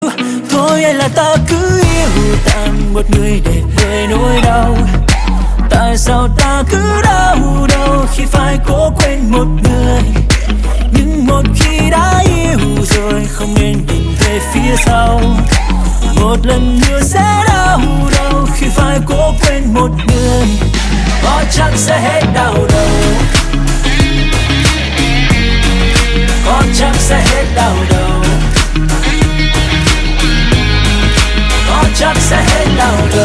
Thể loại nhạc chuông: Nhạc Tik Tok